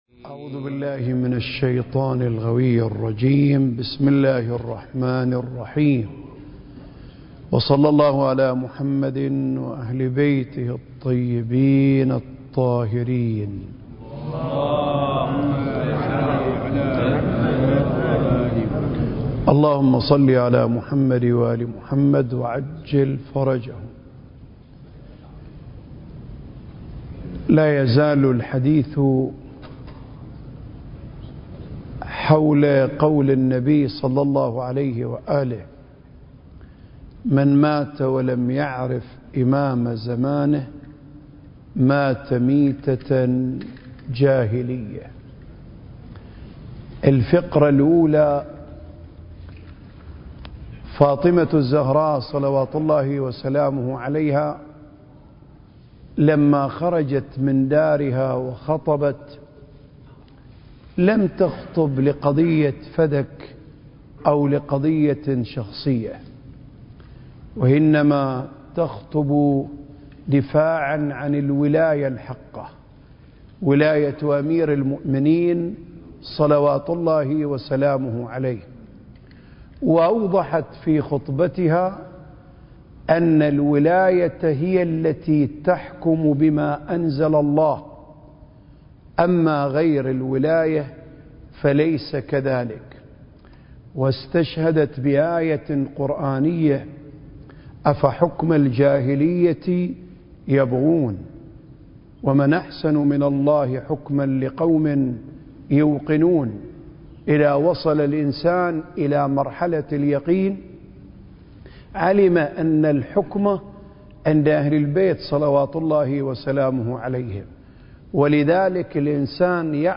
سلسلة محاضرات: آفاق المعرفة المهدوية (8) المكان: الأوقاف الجعفرية بالشارقة التاريخ: 2023